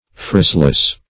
Phraseless \Phrase"less\, a.